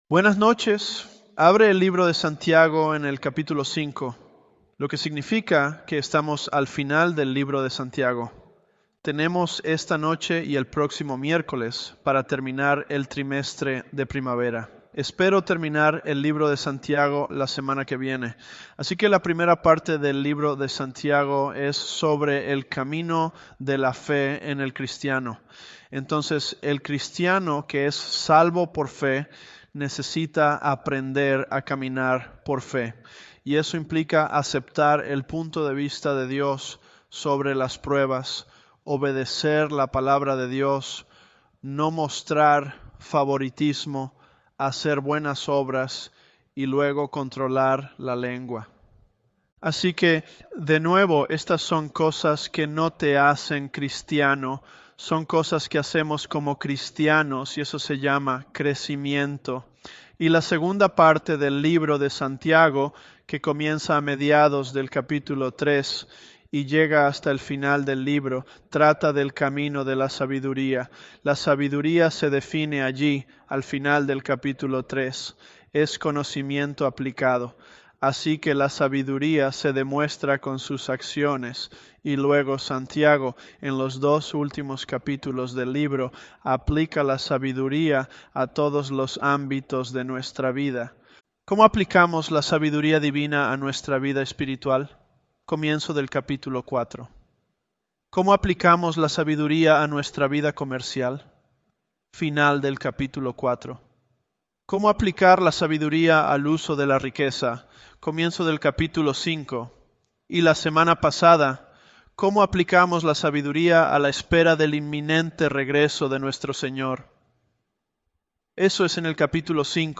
ElevenLabs_James028.mp3